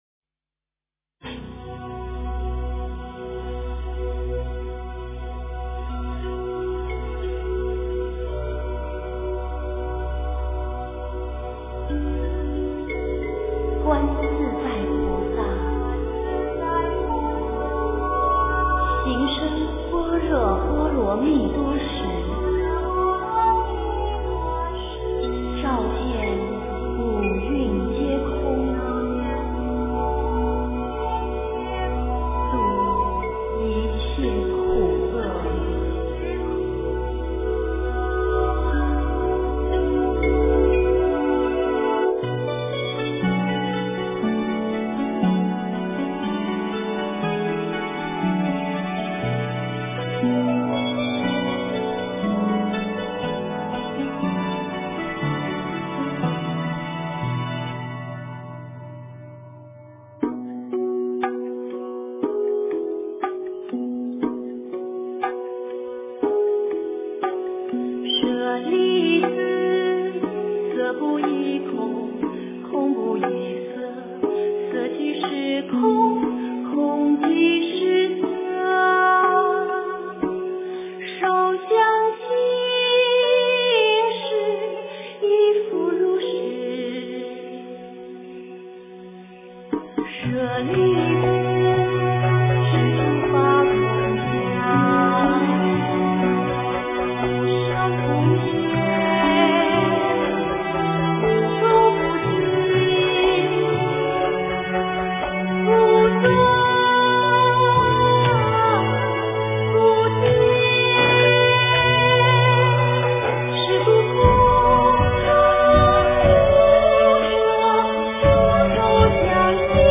佛音 诵经 佛教音乐 返回列表 上一篇： 大悲咒 下一篇： 大悲咒 相关文章 梵唱大悲咒 梵唱大悲咒--群星...